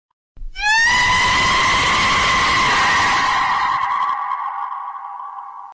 0012_Share_'Chicken_on_tree_screaming'.mp3